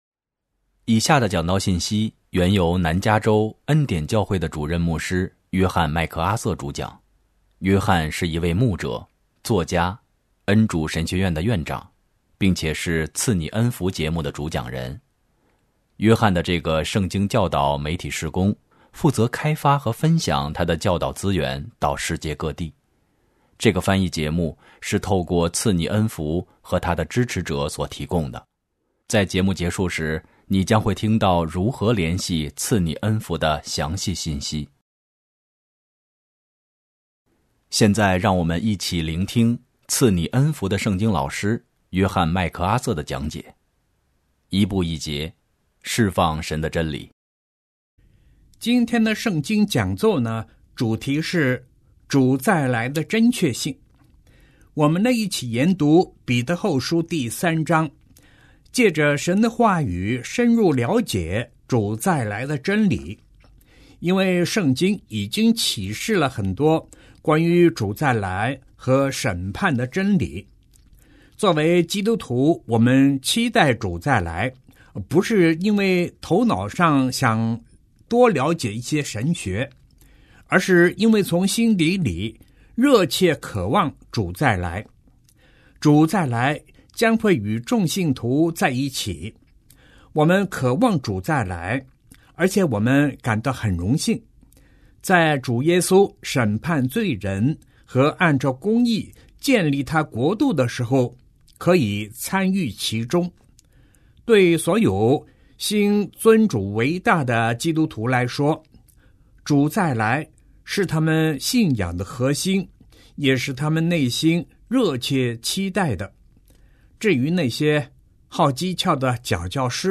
今天的圣经讲座，主题是主再来的真确性。我们一起研读彼得后书3章，借着神的话语，深入了解主再来的真理，因为圣经已经启示了很多关于主再来和审判的真理。